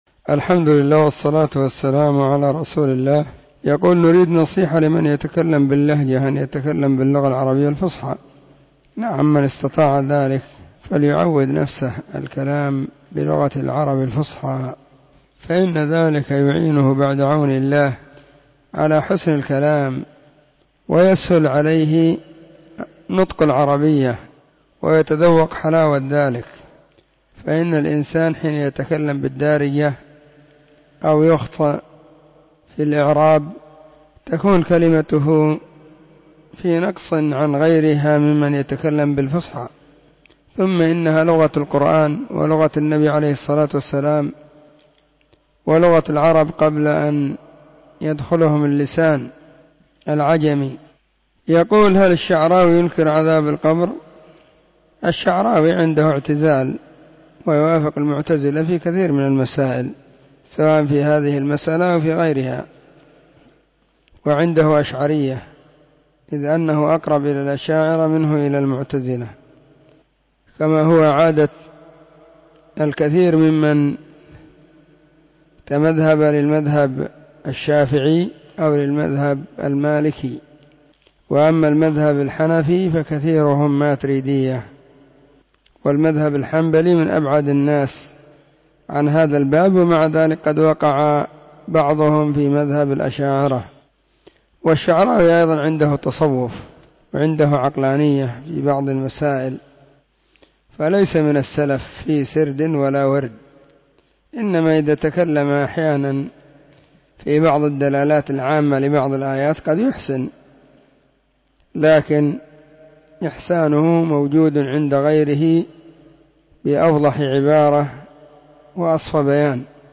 سلسلة الفتاوى الصوتية